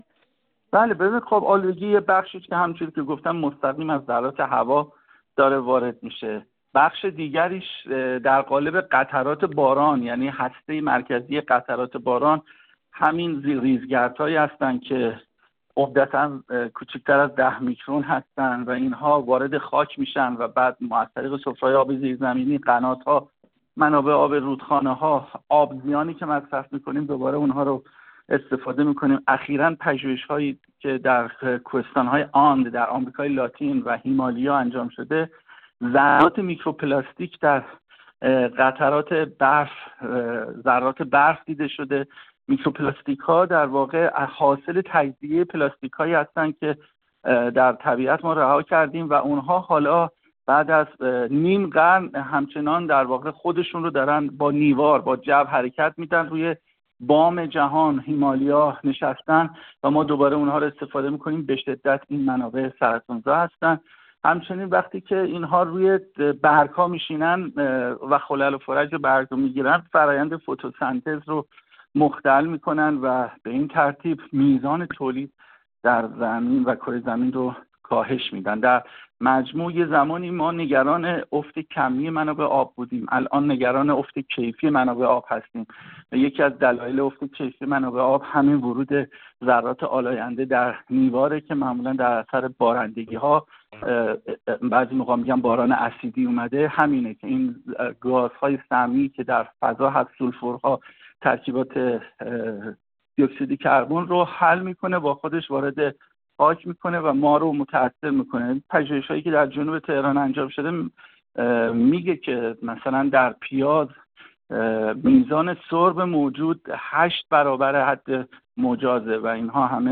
در همین خصوص محمد درویش، کنشگر محیط زیست، پژوهشگر و کویرشناس در گفت‌وگو با ایکنا به تشریح تأثیرات آلودگی هوا بر زندگی و مرگ مردم پرداخت و گفت: مطالعه‌ مرکز پژوهش‌های مجلس در سال 98 نشان می‌دهد آلودگی هوا سالانه بیش از 8.2 میلیارد دلار به اقتصاد کشور خسارت وارد می‌کند.